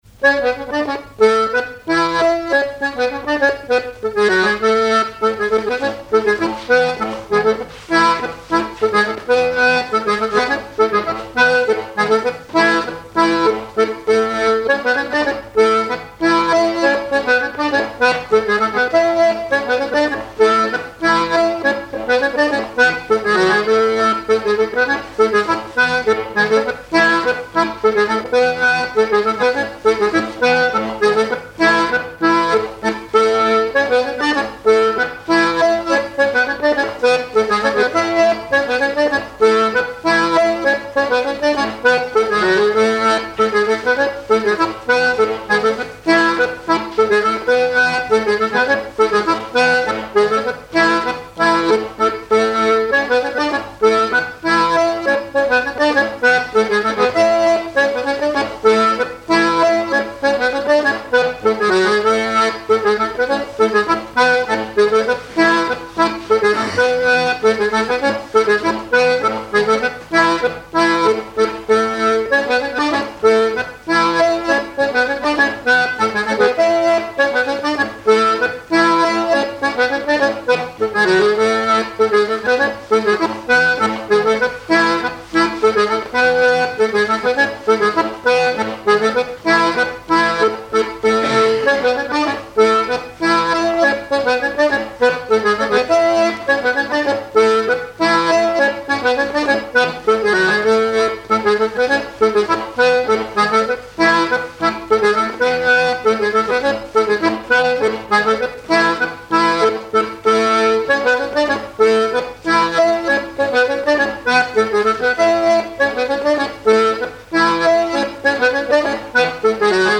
Mans (Le)
danse : pas d'été
airs de danse à l'accordéon diatonique
Pièce musicale inédite